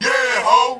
SouthSide Chant (17)(1).wav